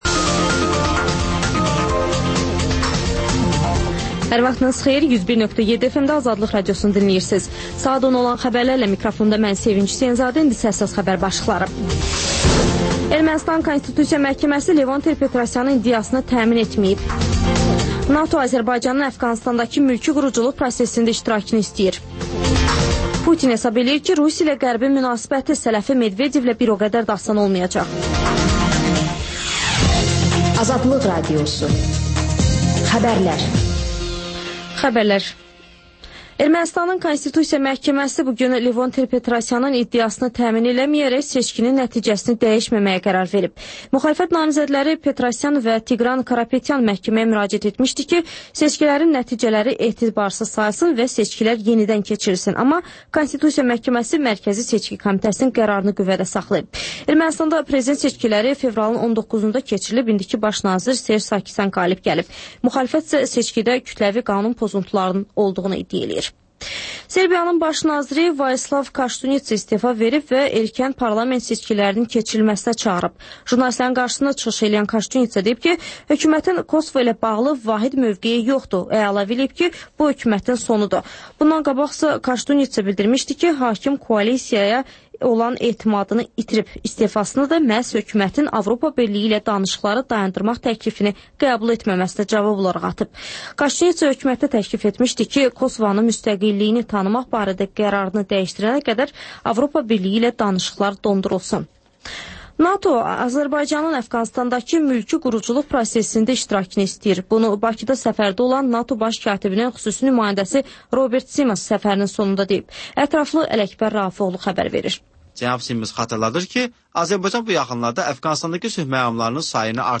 Xəbərlər və XÜSUSİ REPORTAJ: Ölkənin ictimai-siyasi həyatına dair müxbir araşdırmaları